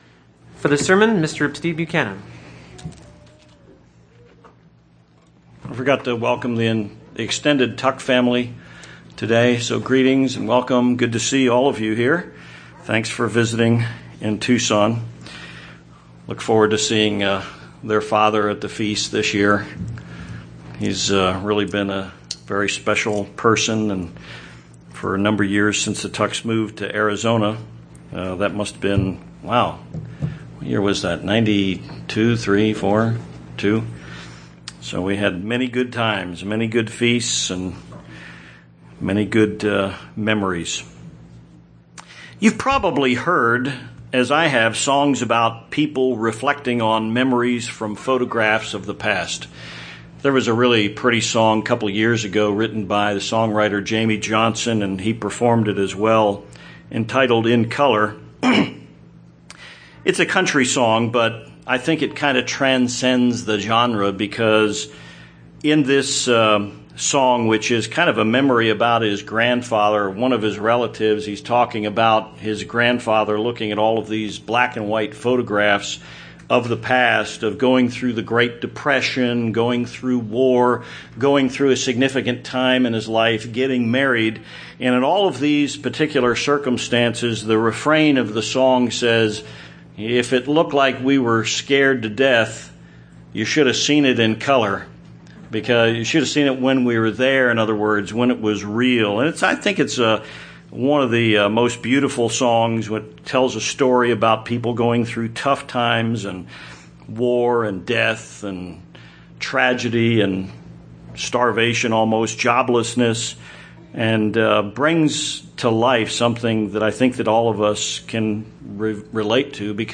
Given in Tucson, AZ
UCG Sermon Studying the bible?